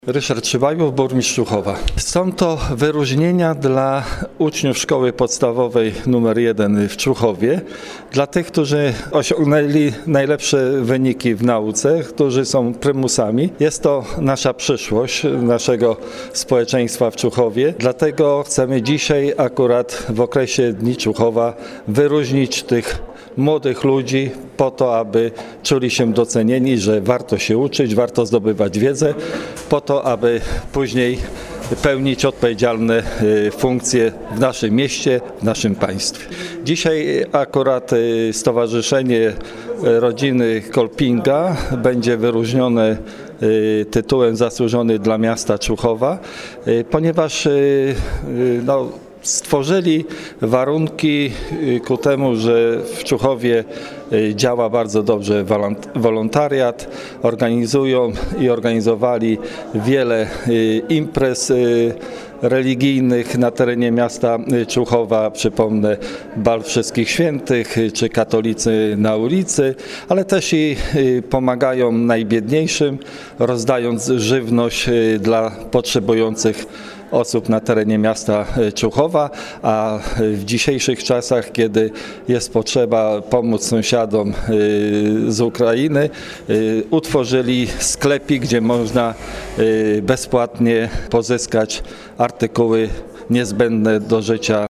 Z okazji Dni Człuchowa 17 czerwca 2022 r. w sali głównej Muzeum Regionalnego na Zamku Krzyżackim odbyła się nadzwyczajna sesja Rady Miejskiej w Człuchowie.